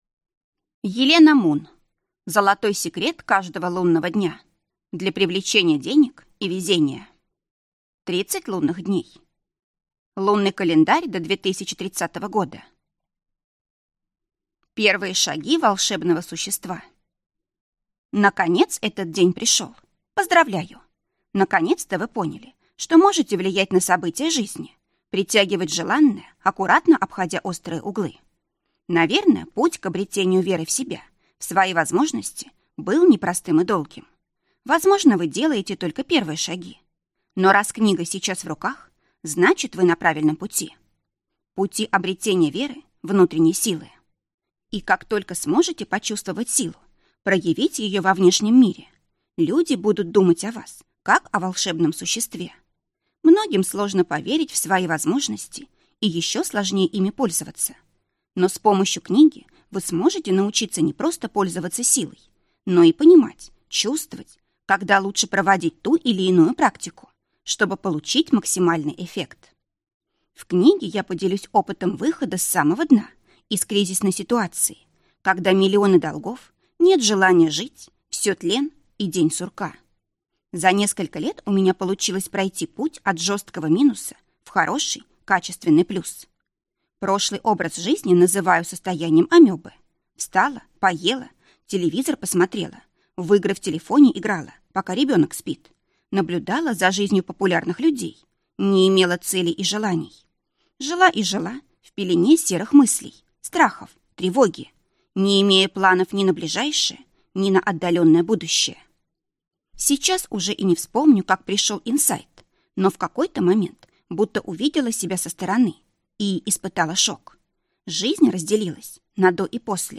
Аудиокнига Золотой секрет каждого лунного дня для привлечения денег и везения. 30 лунных дней.